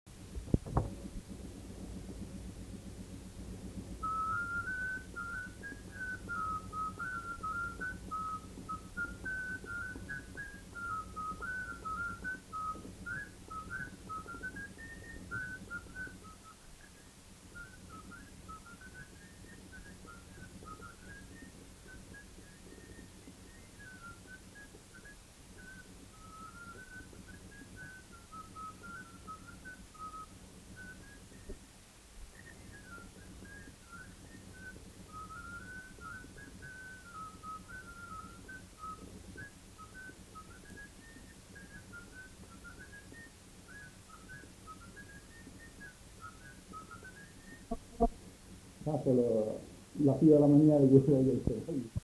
joué à la flûte de Pan et à l'harmonica
Rondeau